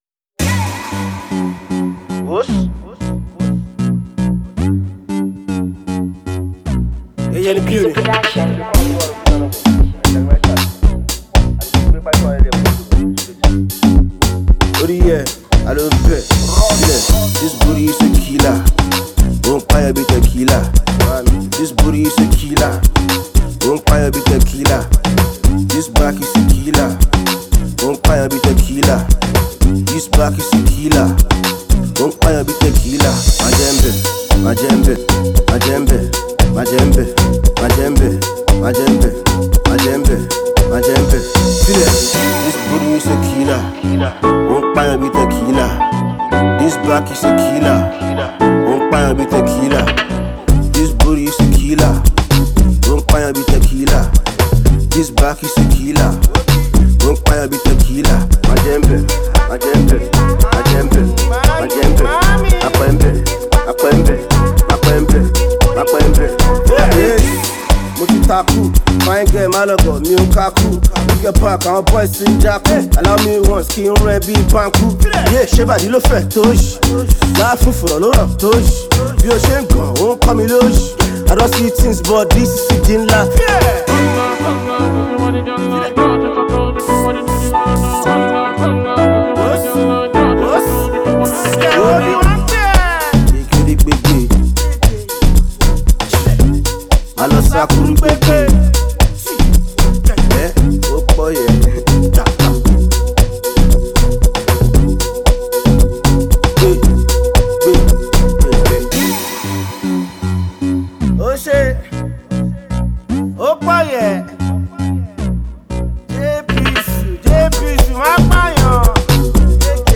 the talented rapper